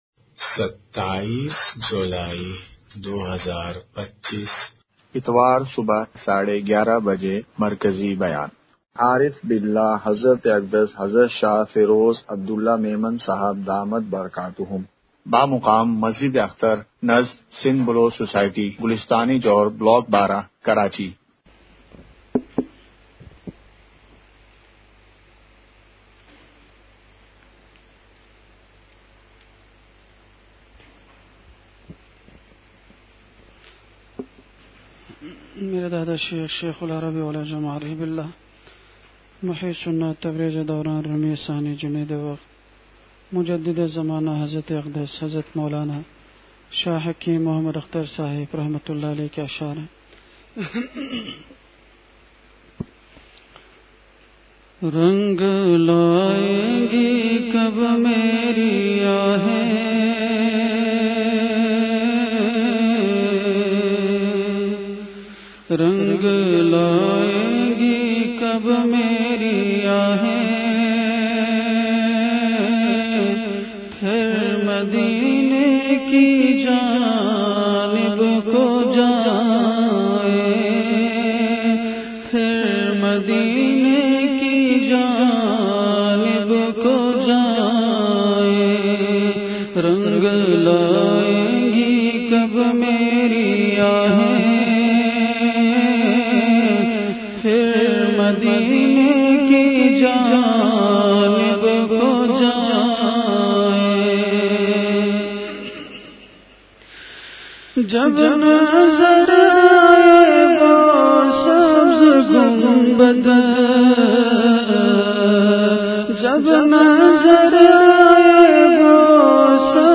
مقام:مسجد اختر نزد سندھ بلوچ سوسائٹی گلستانِ جوہر کراچی
13:11) بیان سے قبل اشعار کی مجلس ہوئی۔۔